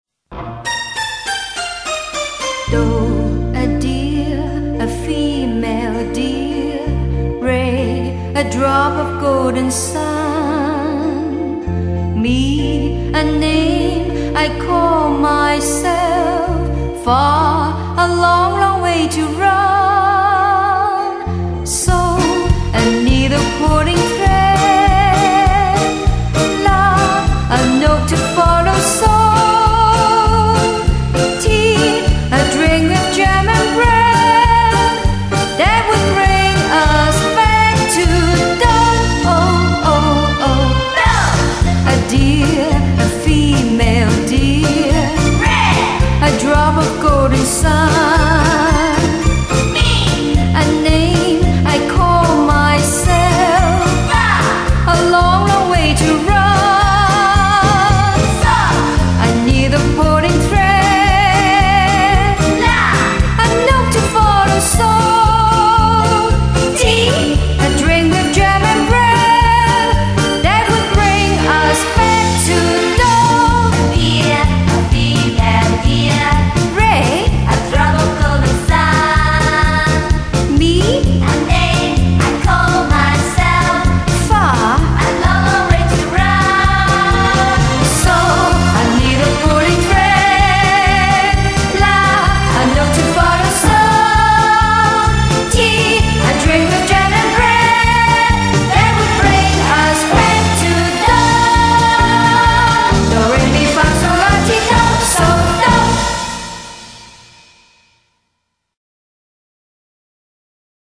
音樂類型：合輯
該專輯不是原唱者演繹，請注意！